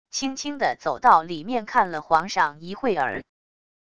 轻轻的走到里面看了皇上一会儿wav音频生成系统WAV Audio Player